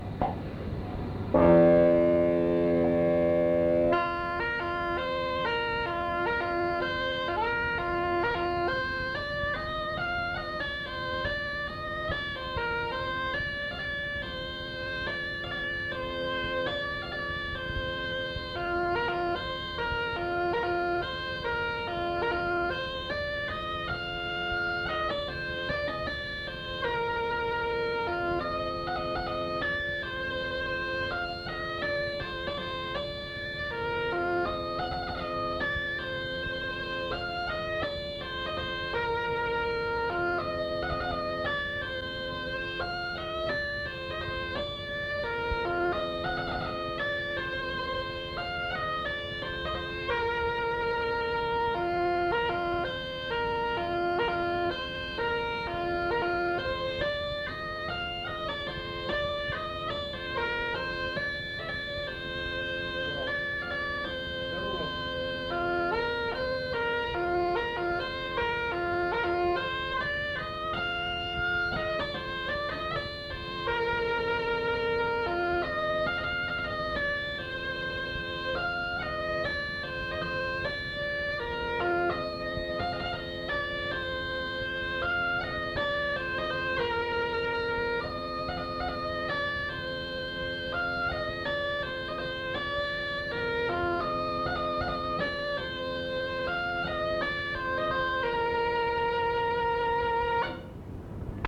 Aire culturelle : Cabardès
Genre : morceau instrumental
Instrument de musique : craba